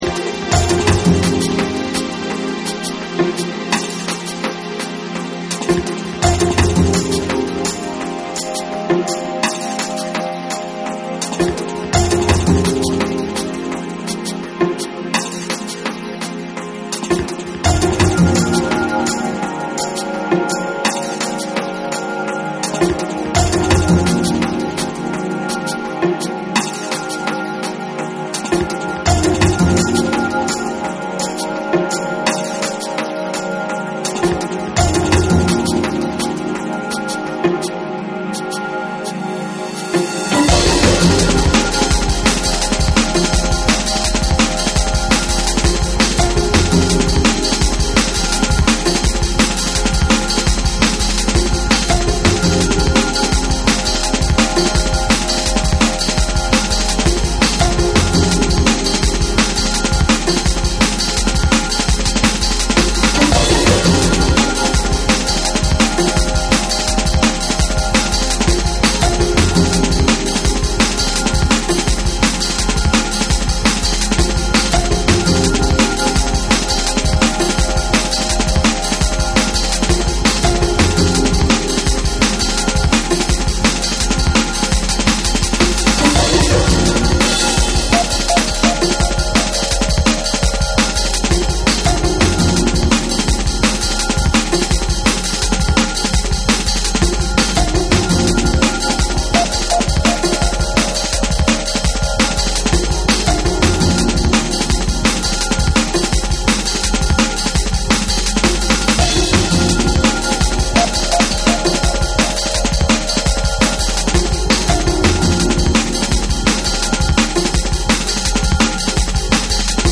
細部まで精密に作りこまれた、生っぽさ際立つリズミカルなトラックを軸に、後半にかけてドラマチックな展開をみせる
タイトに突き進むビートに爽快で広がりのあるシンセが一体となるフューチャリスティックでスペイシーなドラムンベースを披露する
JUNGLE & DRUM'N BASS